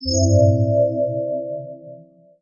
ui_buttonclick.wav